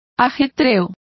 Complete with pronunciation of the translation of bustles.